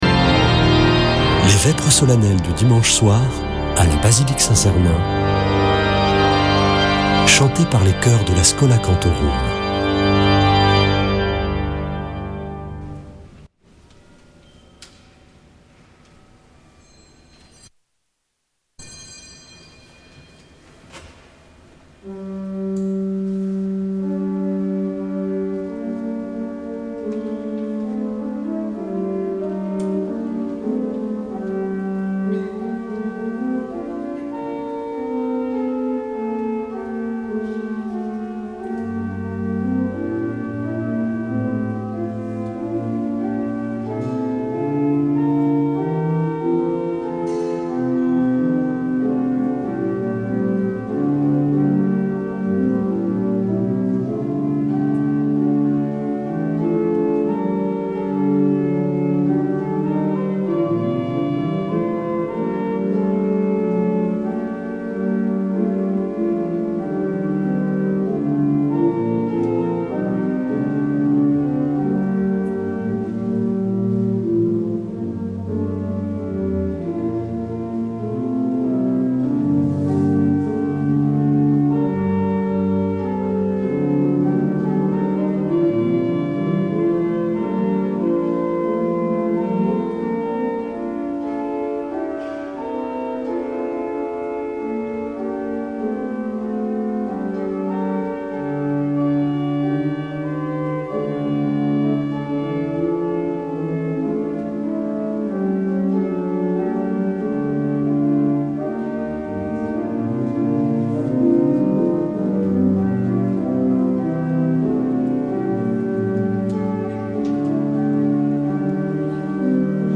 Vêpres de Saint Sernin du 23 févr.